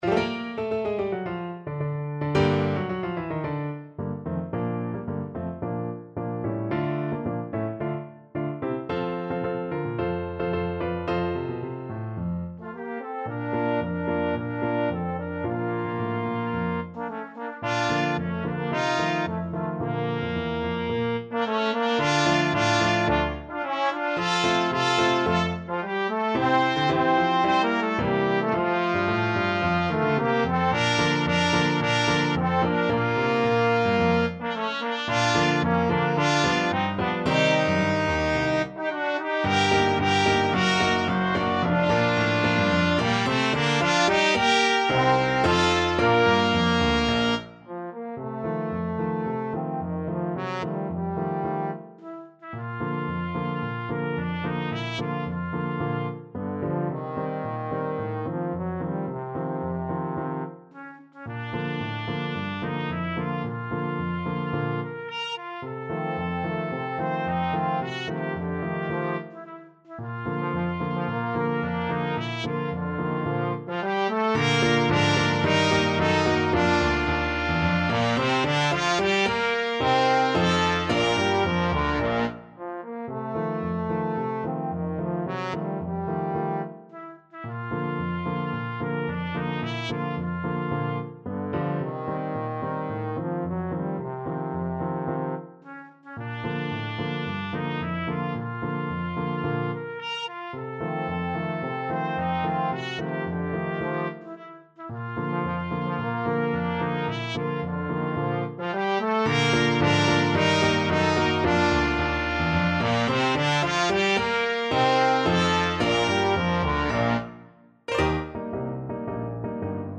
(with piano)
Classical (View more Classical Trumpet-Trombone Duet Music)